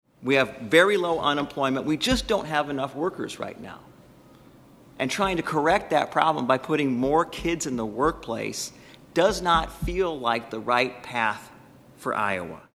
Senator Nate Boulton, a Democrat from Des Moines, says when it comes to children in the workplace, this bill is a step backward.